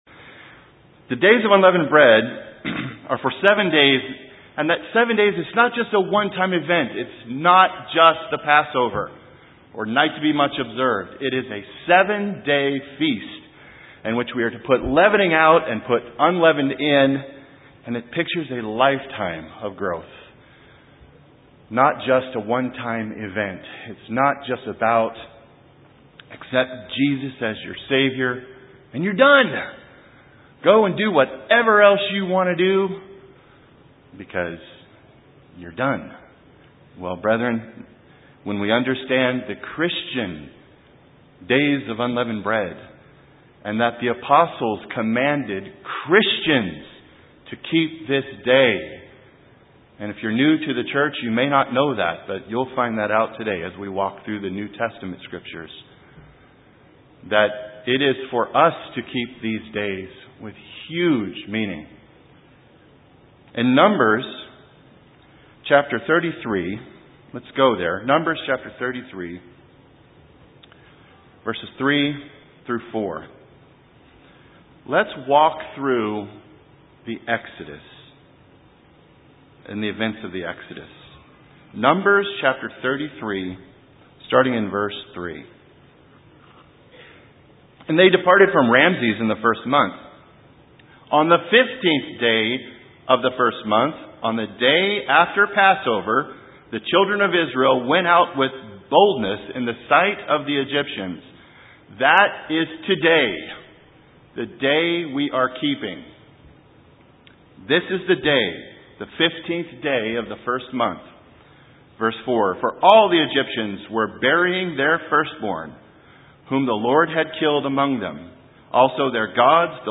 In this sermon we review the Exodus of the Israelites and what is means to us today as the followers of Jesus Christ and putting sin out of our lives.